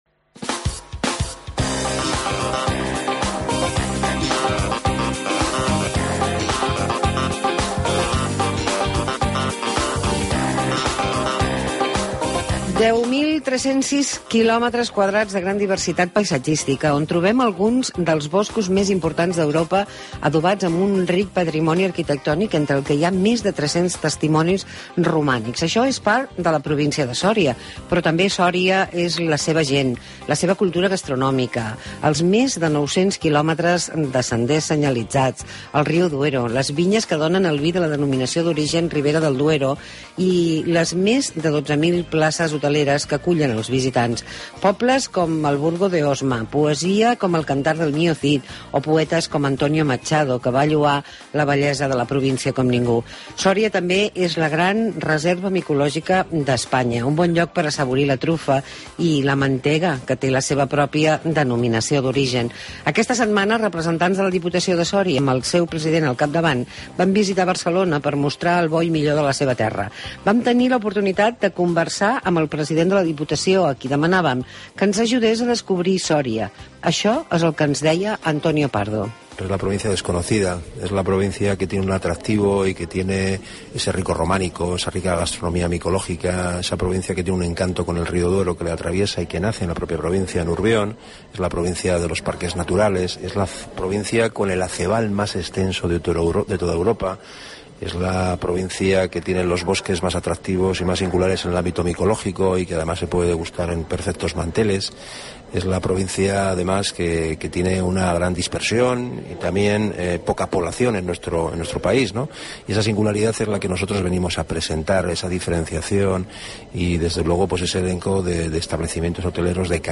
Antonio Pardo President de la Diputació de Soria, ens parla d'aquesta provincia